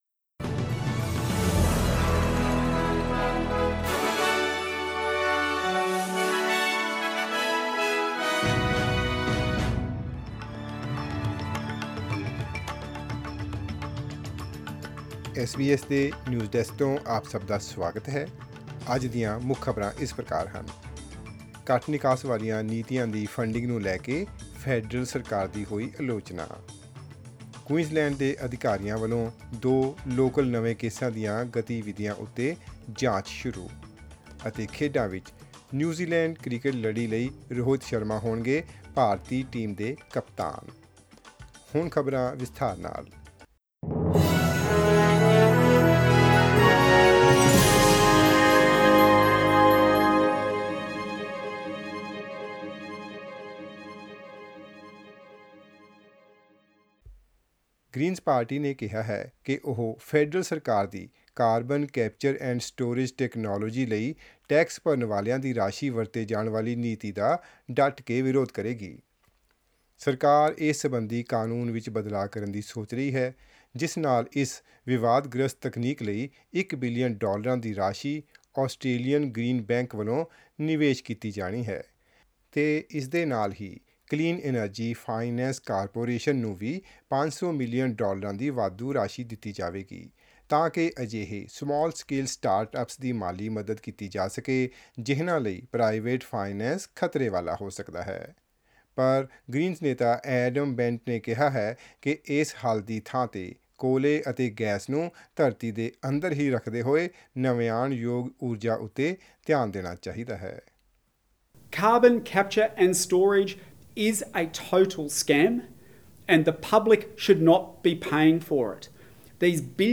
Listen to the latest news headlines in Australia from SBS Punjabi radio.